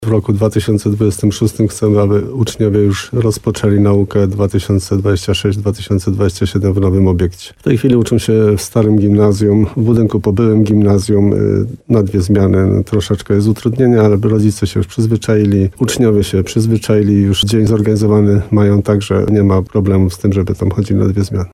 Jak przekonywał w rozmowie Słowo za Słowo w radiu RDN Nowy Sącz wójt Jan Kotarba, prace trwają według zaplanowanego harmonogramu.